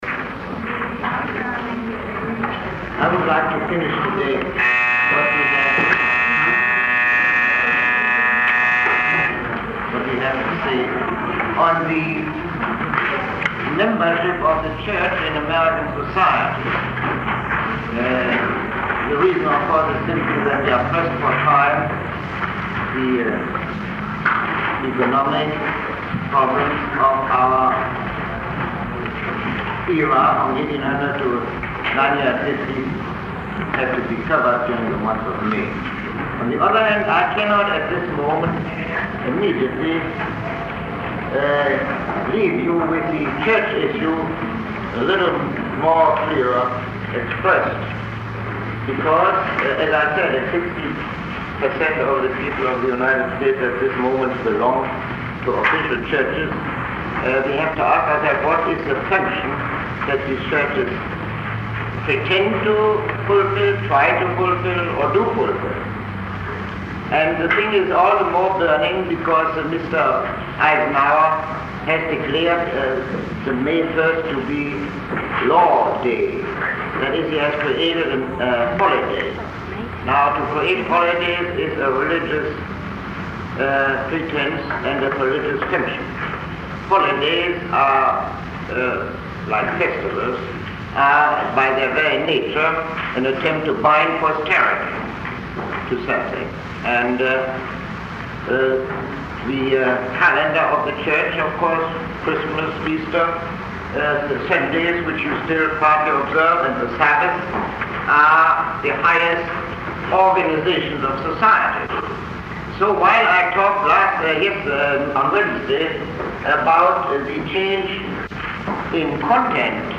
Lecture 32